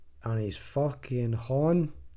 Text-to-Speech
glaswegian
scottish